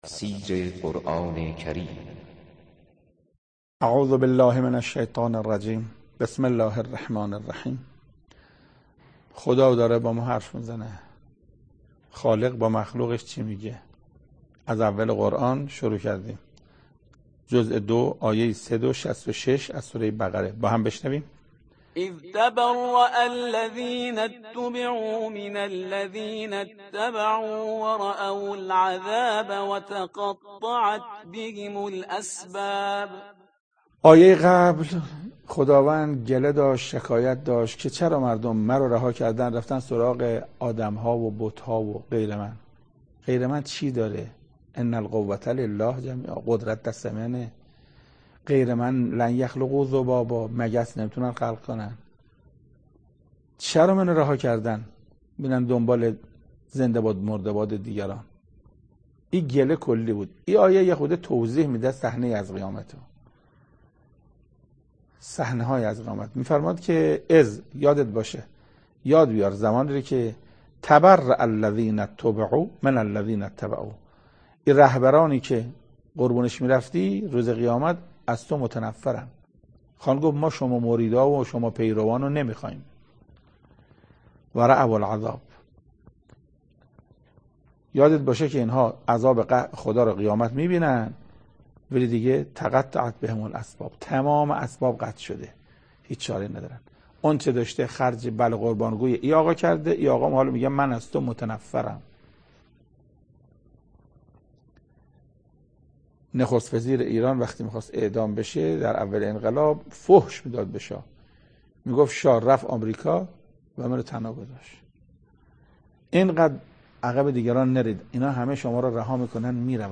سخنرانی محسن قرائتی